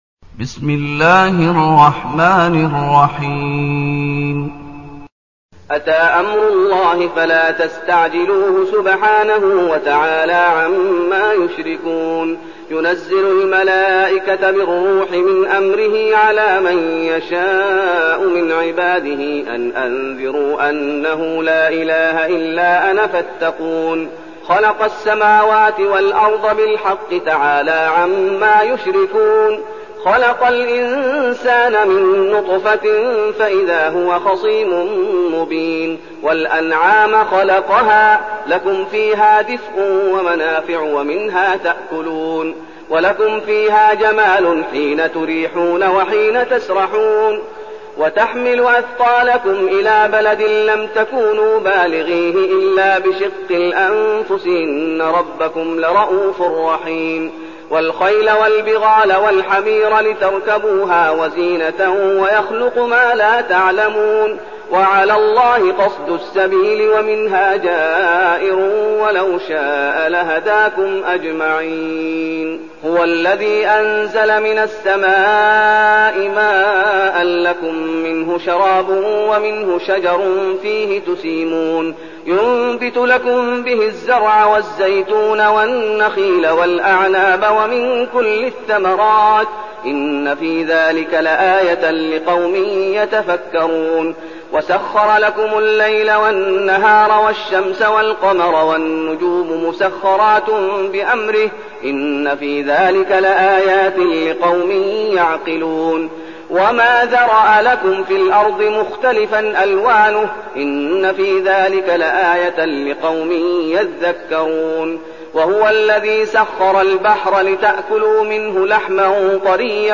المكان: المسجد النبوي الشيخ: فضيلة الشيخ محمد أيوب فضيلة الشيخ محمد أيوب النحل